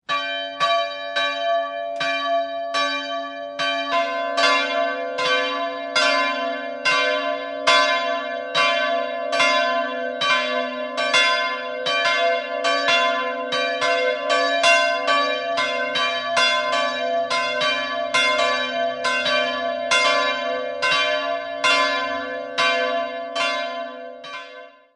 2-stimmiges Geläute: c''-d''